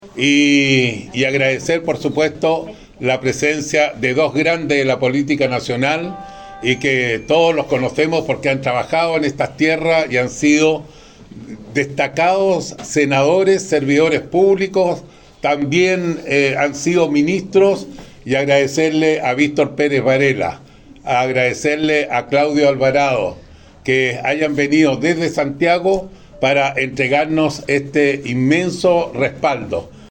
Durante el evento, Zarzar estuvo acompañado por representantes de su equipo de campaña y agradeció profundamente el respaldo de estas figuras clave de la política nacional.